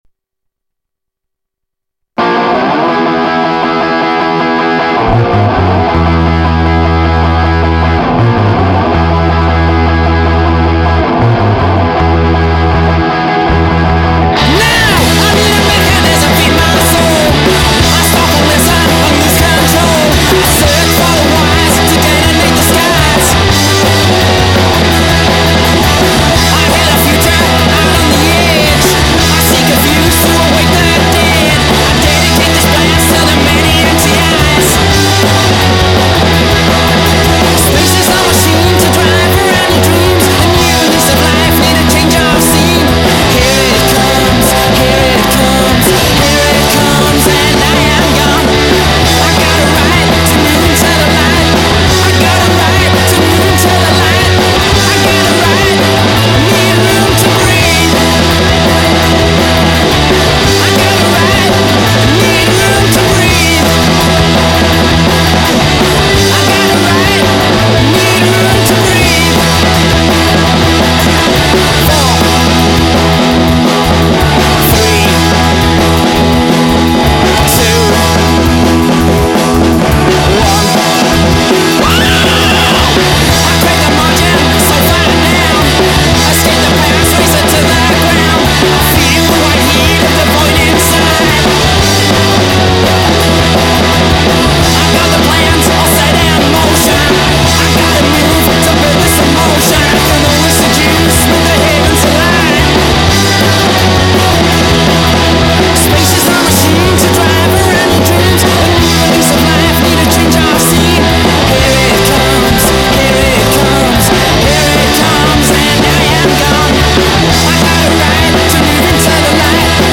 Studio track